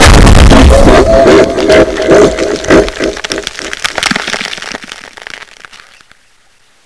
fall1.wav